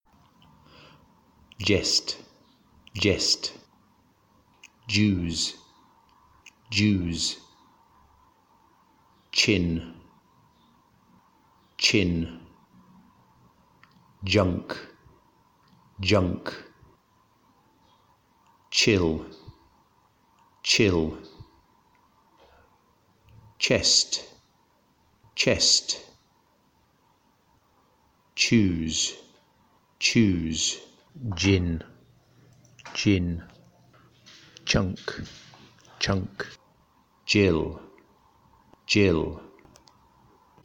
The pronunciation of /ʤ/ and /ʧ/